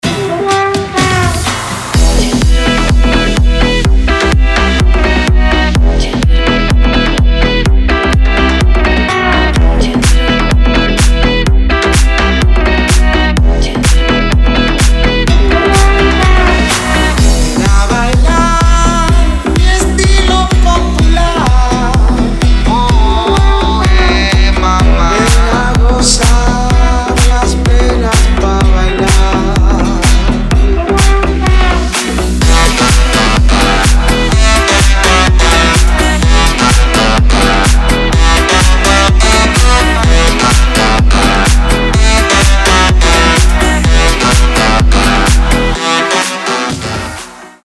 • Качество: 320, Stereo
dance
club
Стиль: deep house